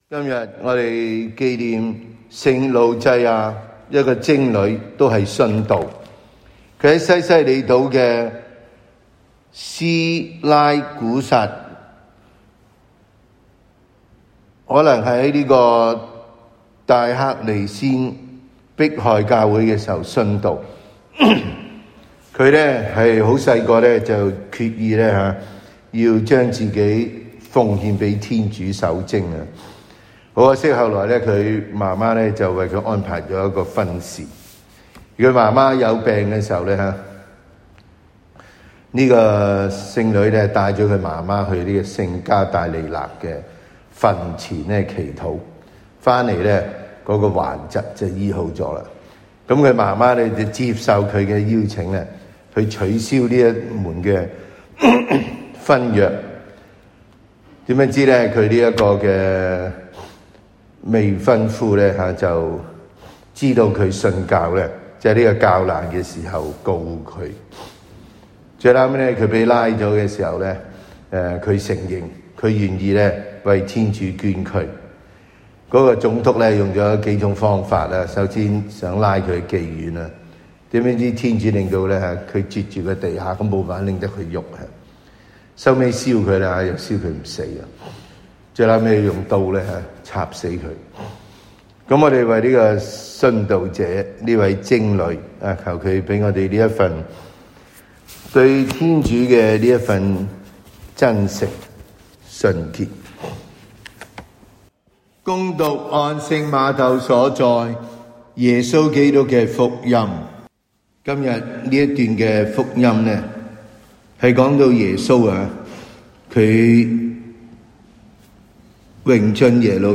感恩祭講道